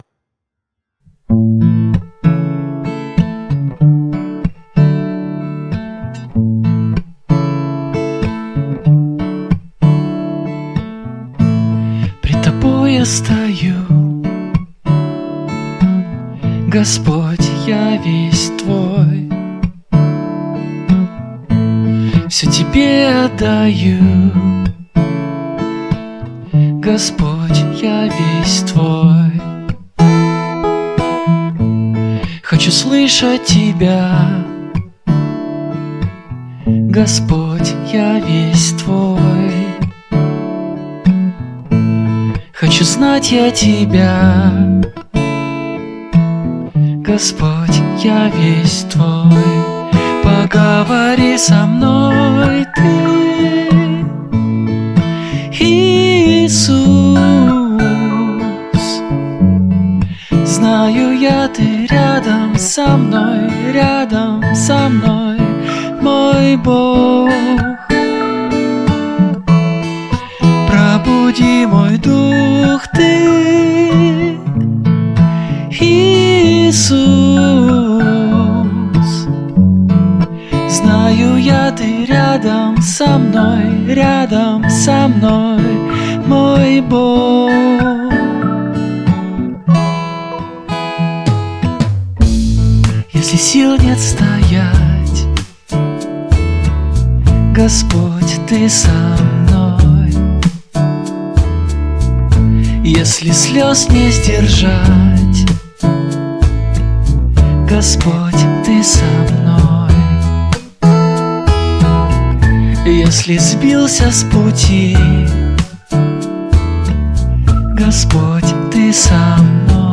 463 просмотра 369 прослушиваний 23 скачивания BPM: 190